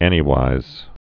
(ĕnē-wīz)